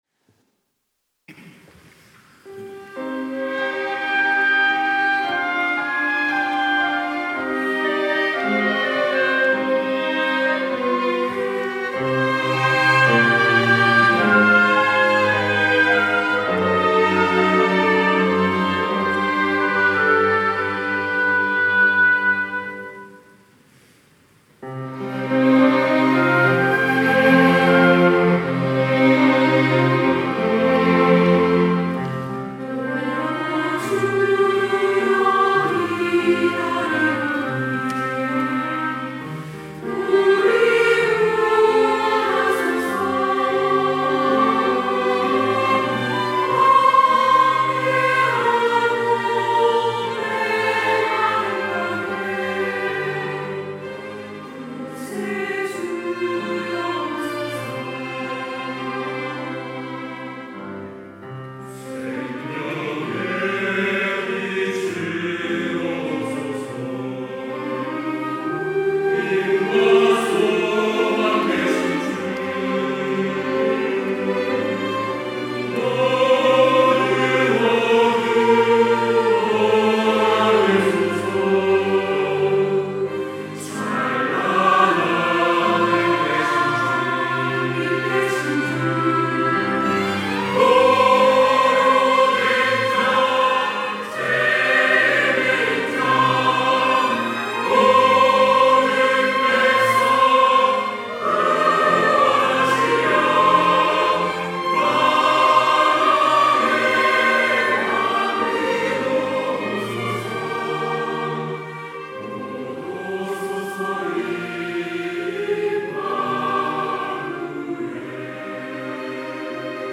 호산나(주일3부) - 주여 기다리오니
찬양대